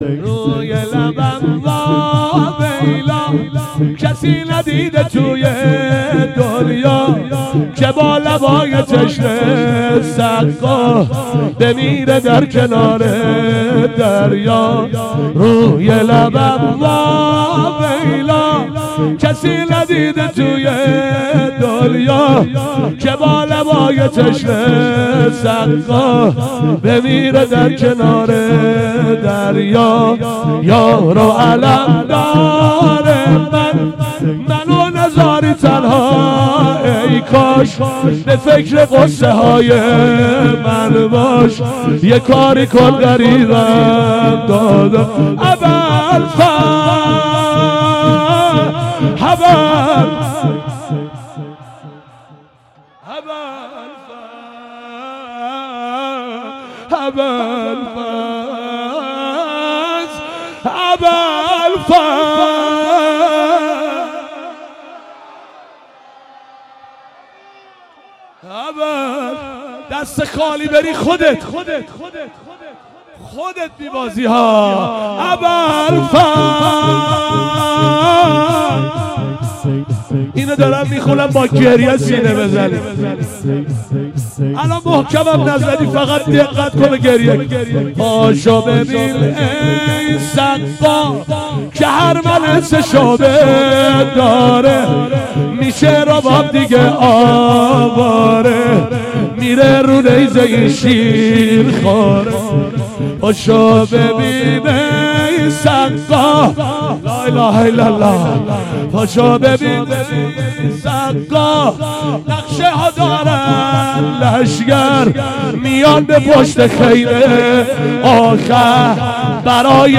گلچین شور